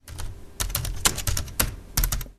keyboard.mp3